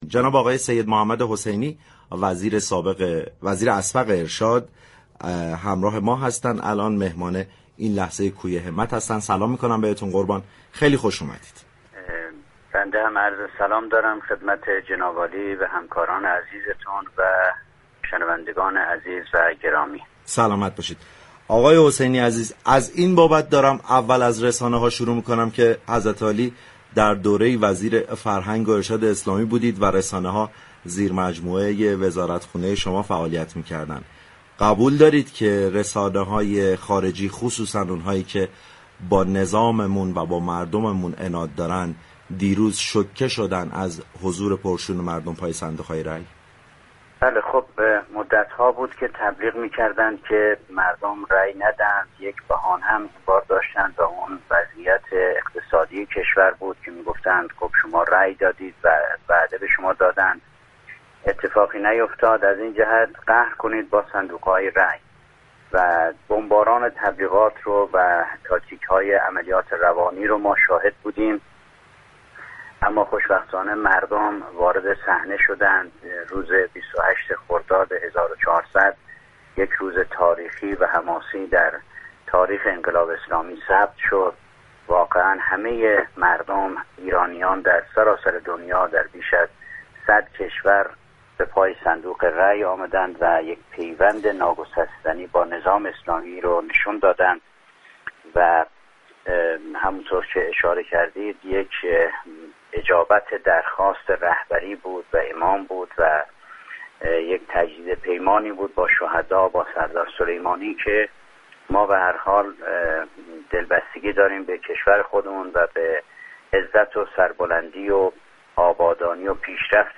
به گزارش پایگاه اطلاع رسانی رادیو تهران، سید محمد حسینی وزیر پیشین وزارت فرهنگ و ارشاد اسلامی در گفتگو با ویژه برنامه انتخاباتی كوی همت رادیو تهران گفت: مدت‌ها بود كه از جانب دشمنان كشورمان شاهد بمباران تبیلغاتی و تاكتیك‌های عملیات روانی بودیم و مردم را تشویق می‌كردند كه در انتخابات شركت نكنند اما مردم روز گذشته وارد صحنه شدند و 28 خرداد سال 1400 به عنوان یك روز تاریخی و حماسی دیگر در تاریخ انقلاب اسلامی كشورمان ثبت شد.